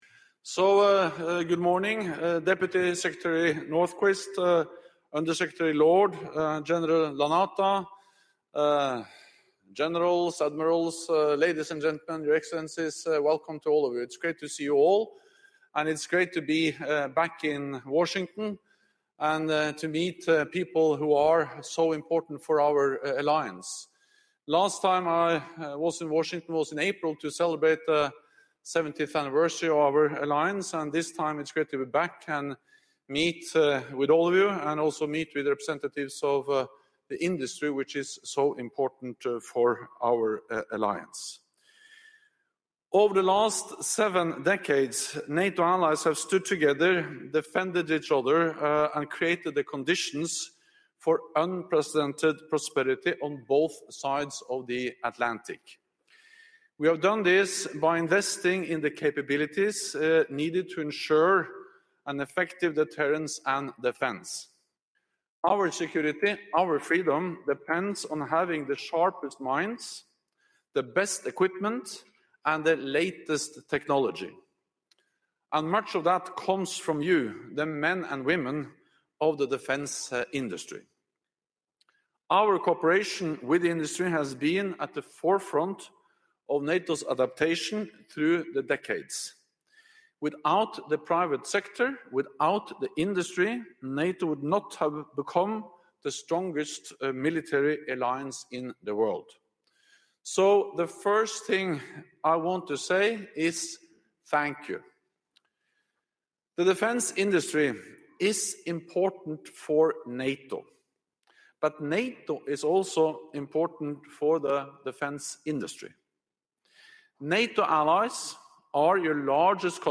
Secretary General Jens Stoltenberg underlined the importance of NATO’s cooperation with industry in a keynote speech at the NATO-Industry Forum in Washington, D.C. on Thursday (14 November 2019). Noting that Allies are industry’s largest customers, he set out three ways in which NATO plays a decisive role: in setting guidelines for how much Allies spend on defence, what they spend it on, and how this money is spent.